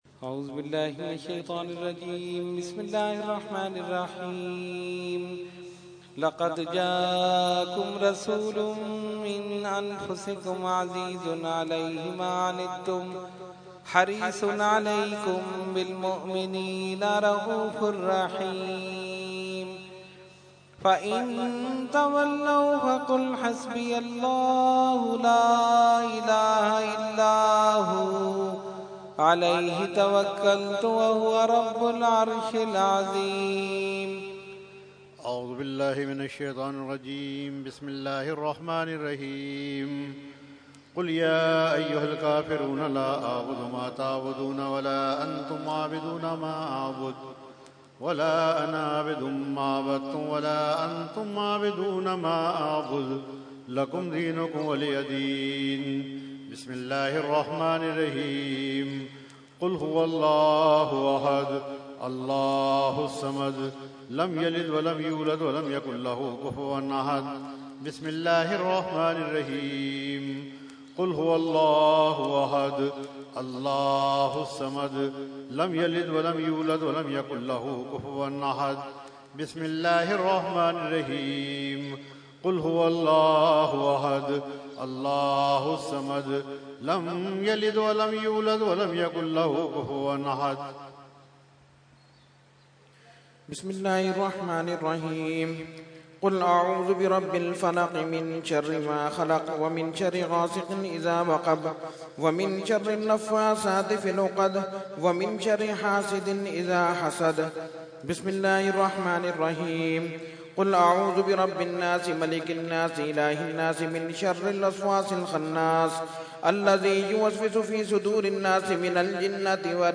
Fatiha Dua – Urs Qutbe Rabbani 2015 – Dargah Alia Ashrafia Karachi Pakistan
17-Fatiha and Dua.mp3